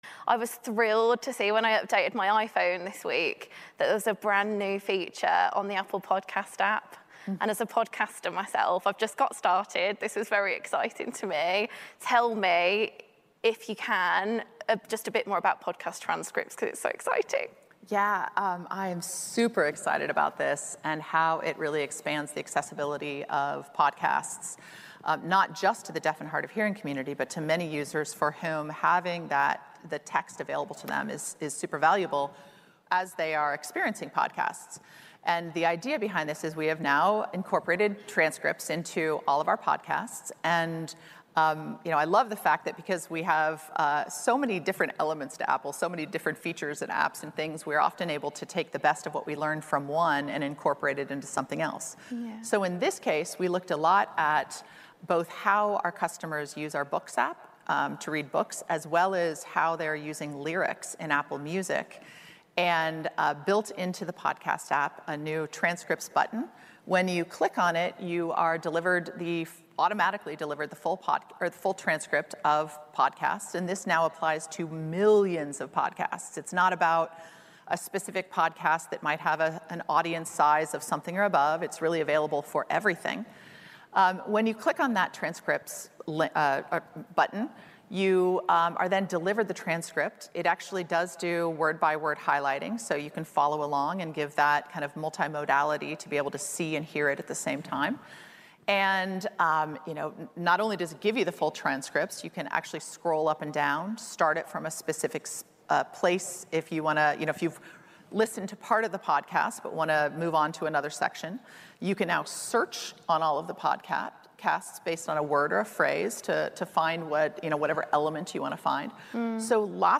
(rarely do you get someone from Apple talking at a public non-Apple event)